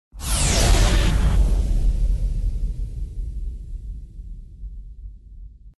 Здесь вы найдете эффекты телепортации, хронопрыжков, искривления пространства-времени и других фантастических явлений.